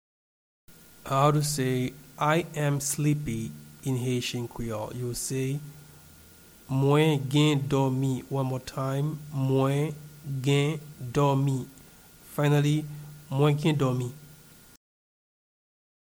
Pronunciation and Transcript:
I-am-sleepy-in-Haitian-Creole-Mwen-gen-domi-pronunciation.mp3